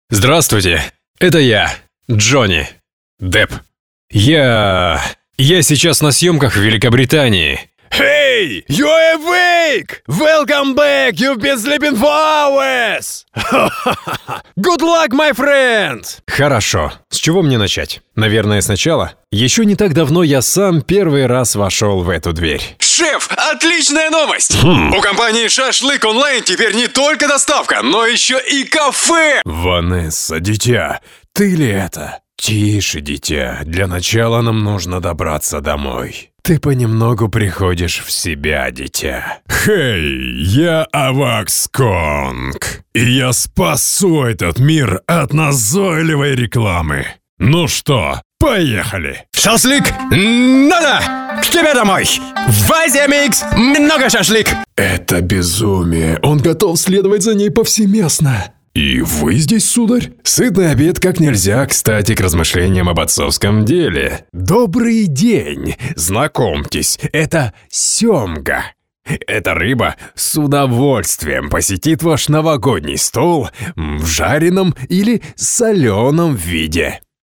Russian Male Voice Over Artist
Male
Authoritative, Bright, Character, Confident, Cool, Corporate, Deep, Engaging, Friendly, Natural, Sarcastic, Soft, Warm, Versatile
High-quality professional equipment and a sound booth are used.
You get a clean and ready-to-use voice-over. 100% Russian - No accent.
Microphone: Rode K2, Sennheiser MKH 416